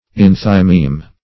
enthymeme - definition of enthymeme - synonyms, pronunciation, spelling from Free Dictionary
Enthymeme \En"thy*meme\, n. [Gr.